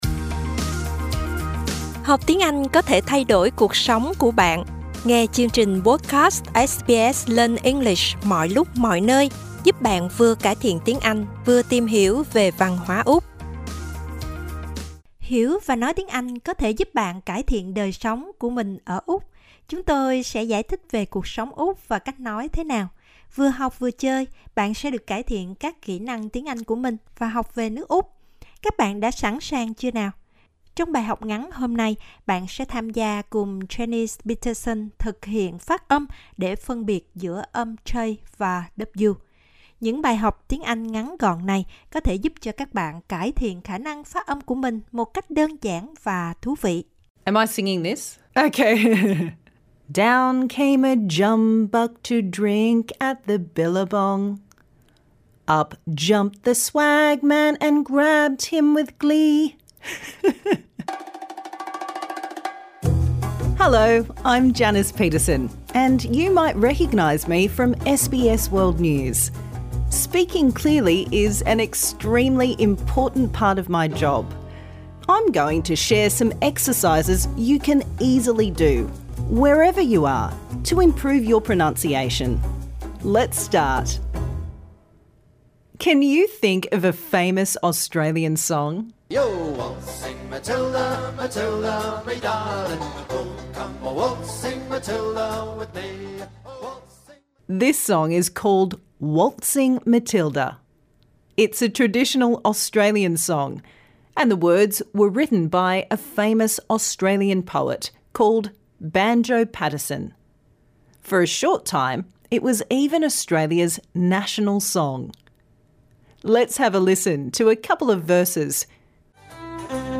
This lesson suits all learners at all levels.